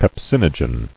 [pep·sin·o·gen]